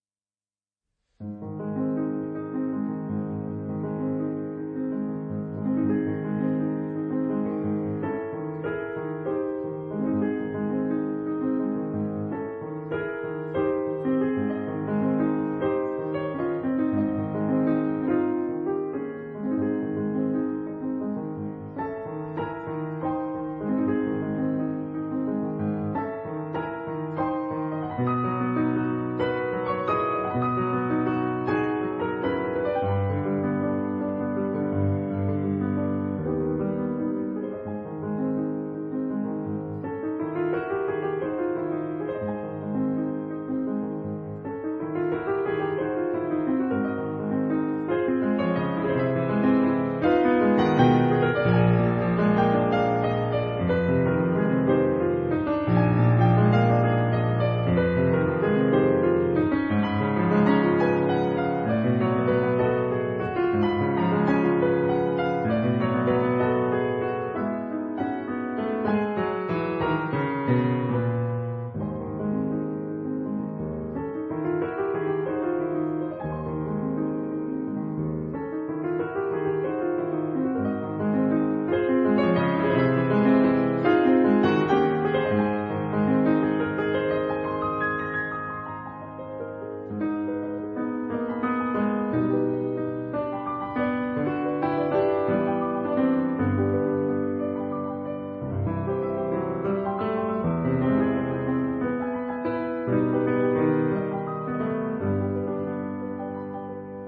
這是個充滿多變的時代：後期浪漫、新古典、現代主義、印象派、爵士⋯⋯